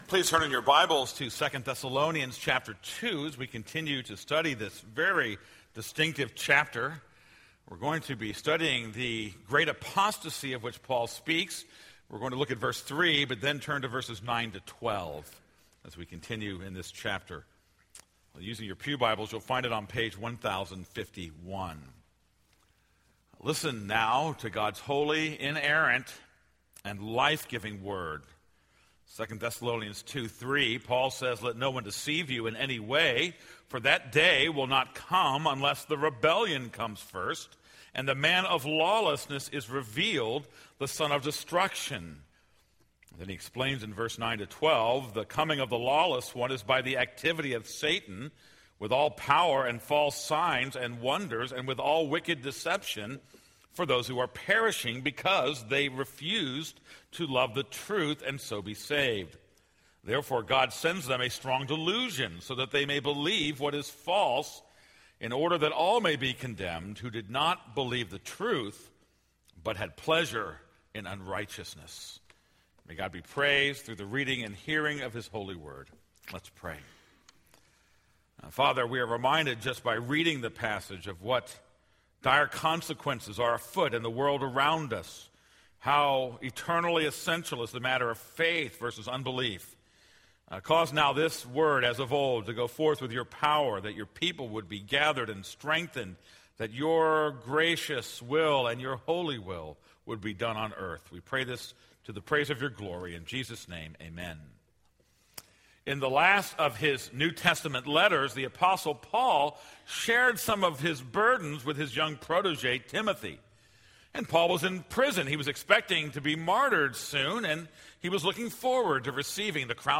This is a sermon on 2 Thessalonians 2:3; 9-12.